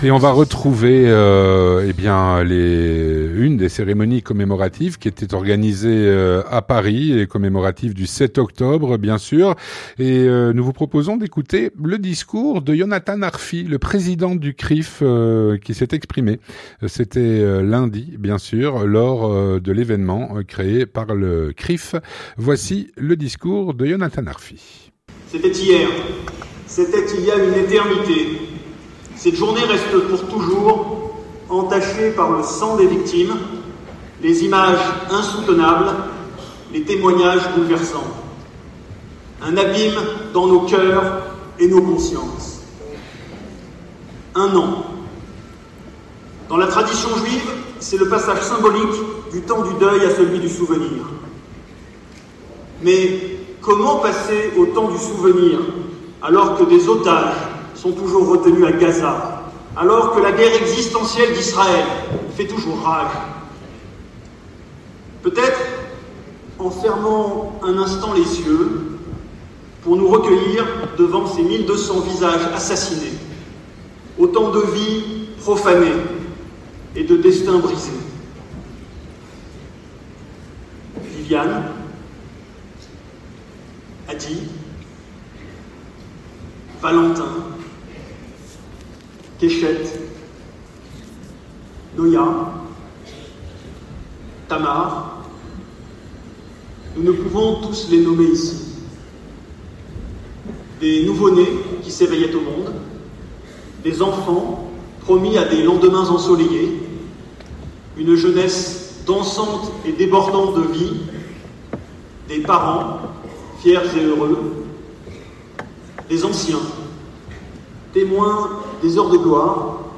Discours
lors des cérémonies du 7 octobre à Paris.
Un reportage, sur place, à Paris